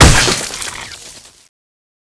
hit_flesh1.wav